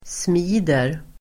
Uttal: [sm'i:der]